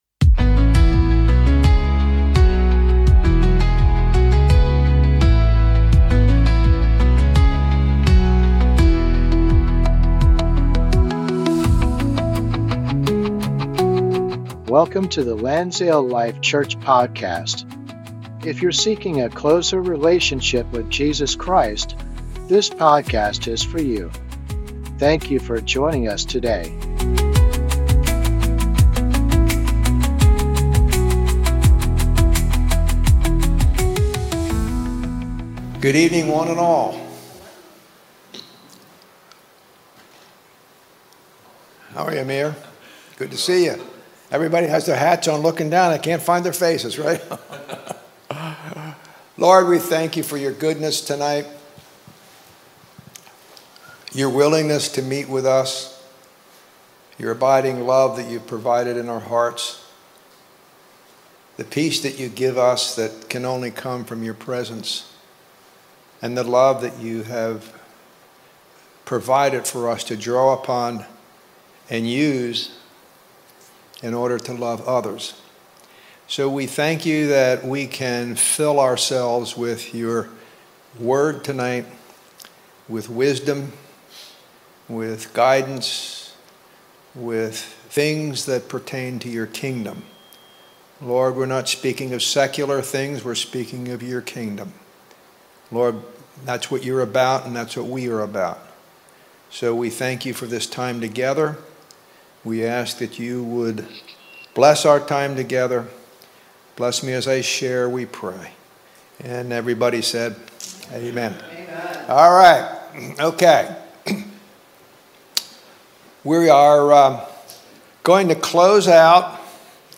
Click to listen, free! 104 episodes in the Christianity genre.